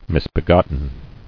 [mis·be·got·ten]